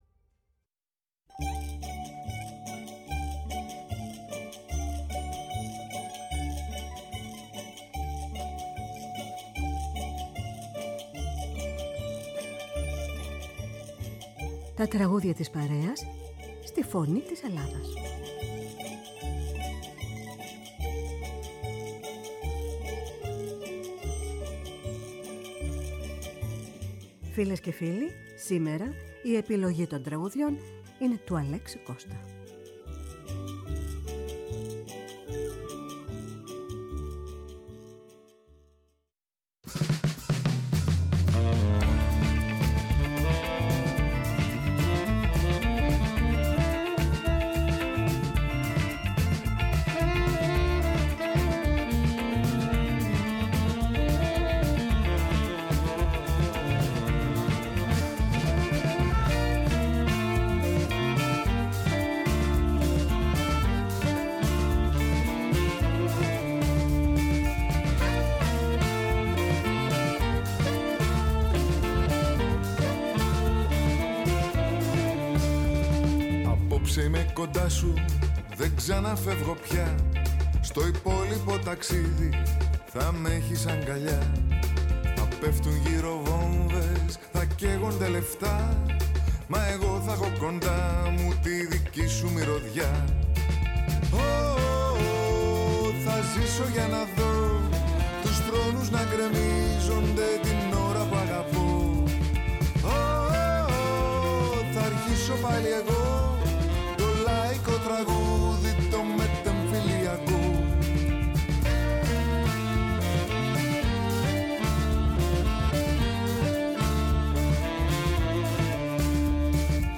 Με μουσικές από την Ελλάδα και τον κόσμο.